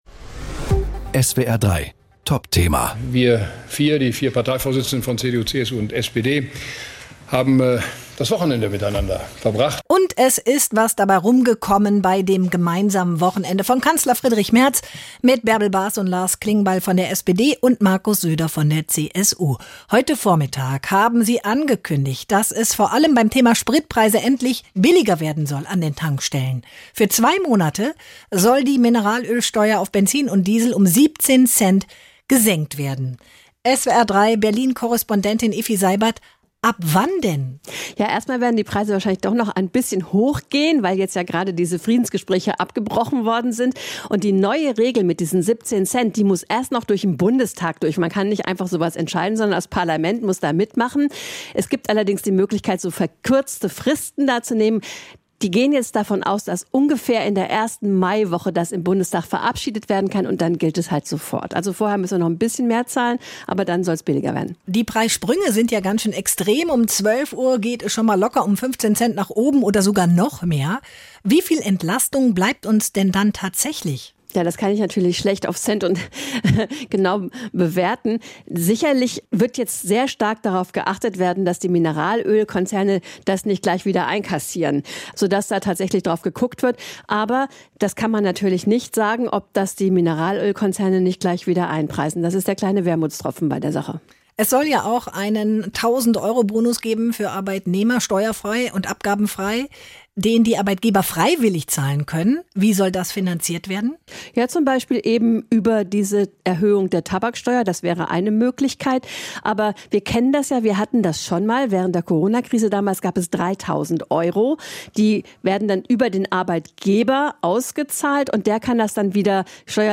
Nachrichten
Einschätzungen aus dem SWR3 Hauptstadtstudio.